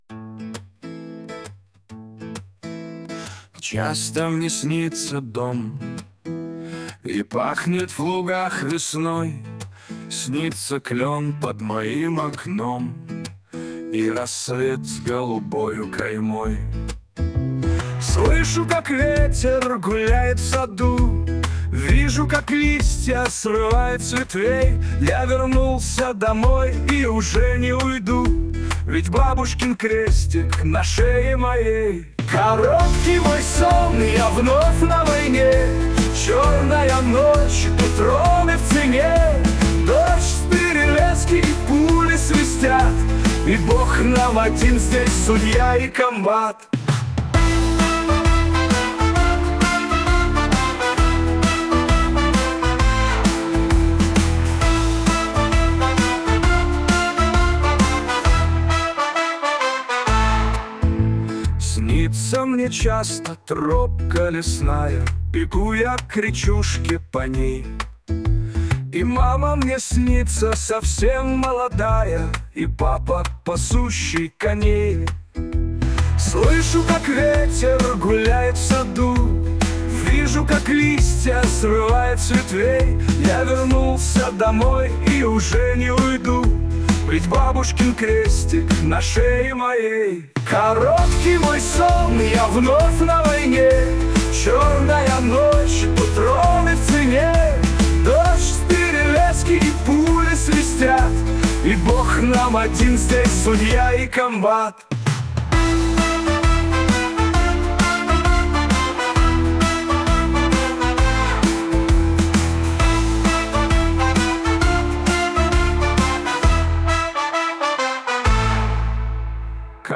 песня сгенерирована автором в нейросети